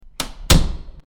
Door Closing
Door_closing.mp3